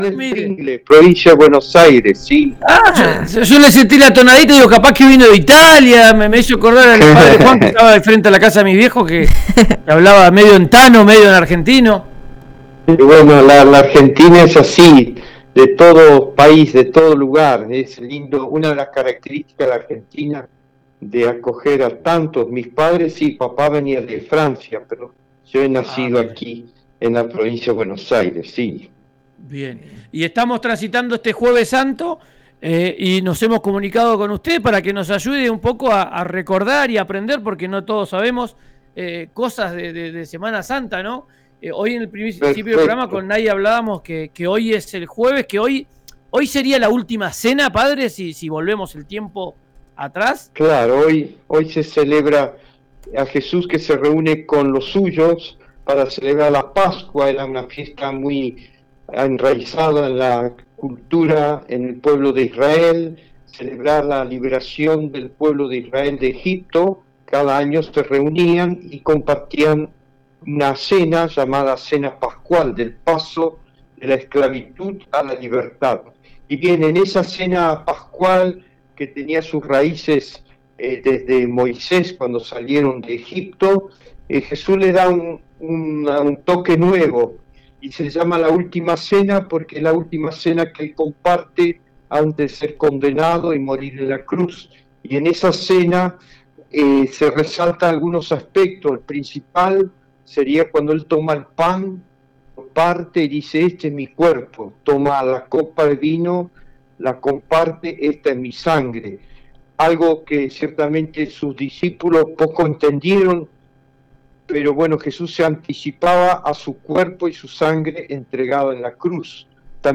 El obispo de Viedma, Esteban Laxague, explicó las festividades y actividades que realiza la Iglesia Católica.